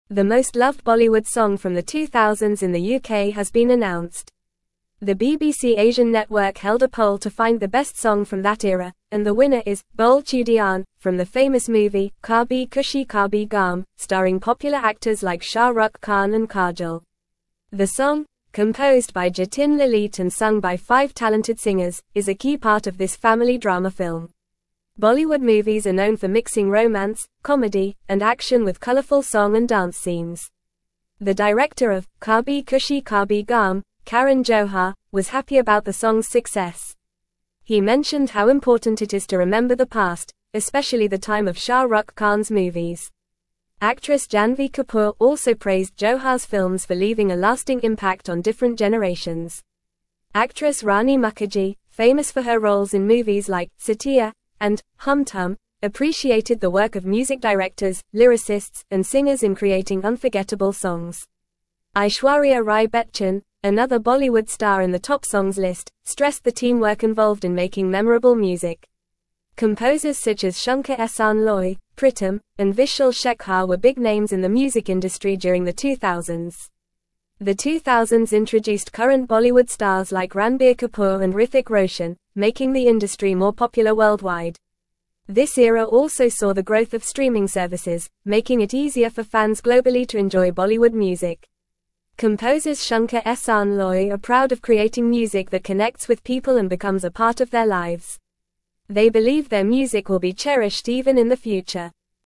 Fast
English-Newsroom-Upper-Intermediate-FAST-Reading-UKs-Favorite-Bollywood-Song-from-Noughties-Revealed.mp3